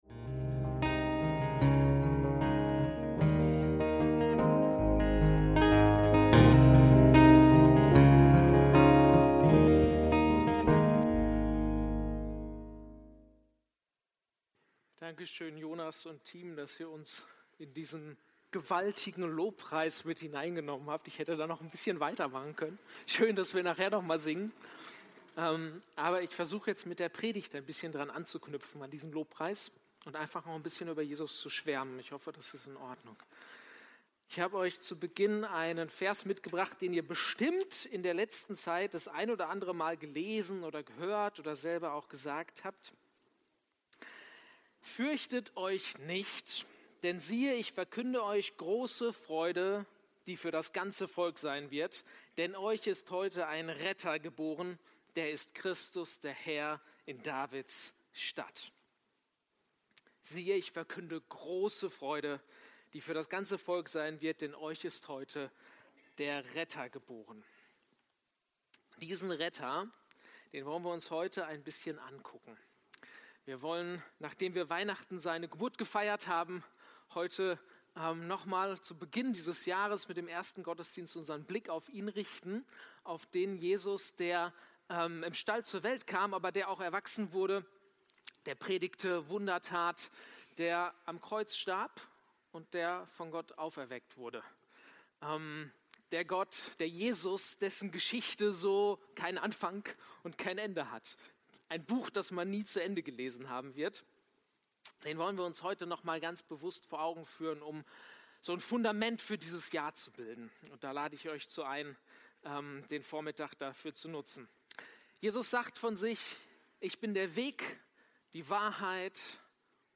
Jesaja 61,1-3 – Predigt vom 04.01.2026